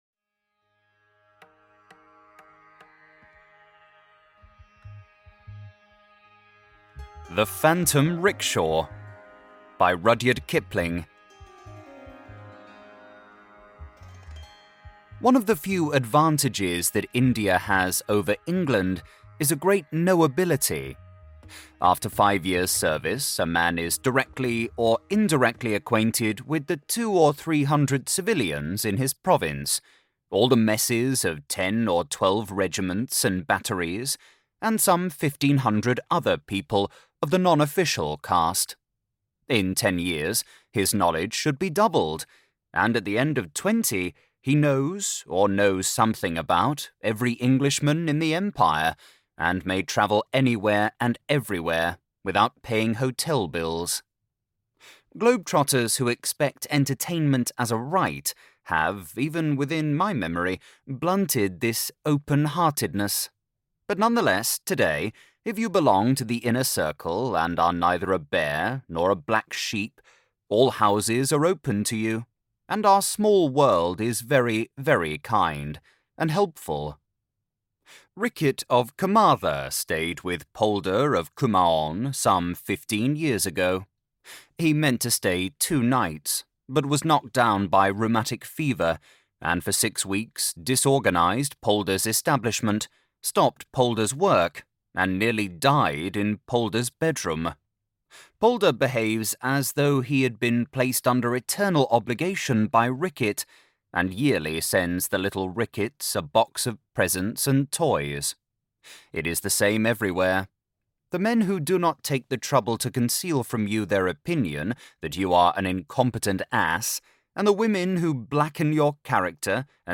Gibbet Hill by Bram Stoker - Lost Horror Short Story Audiobook